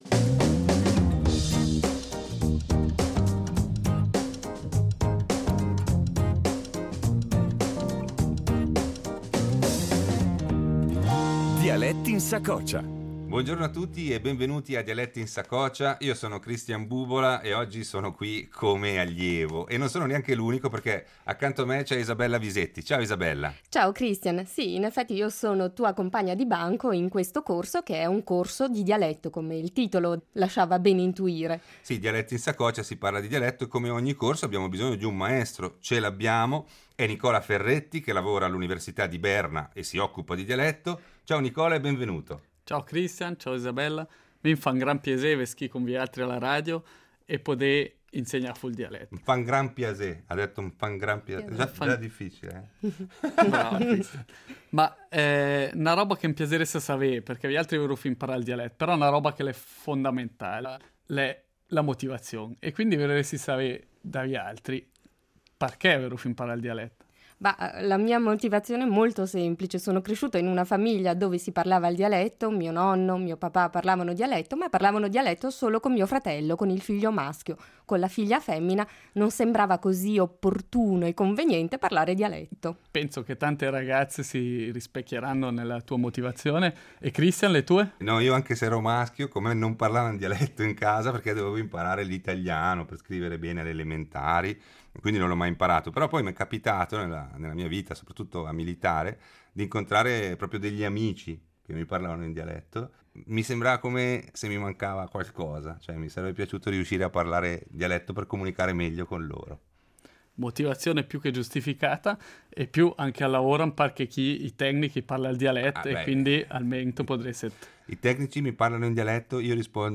Prima lezione del corso di dialetto